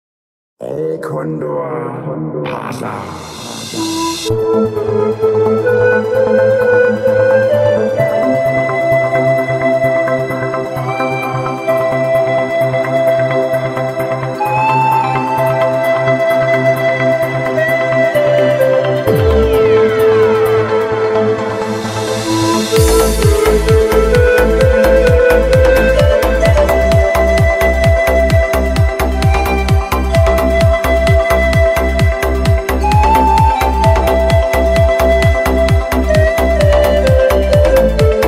Instrumentalny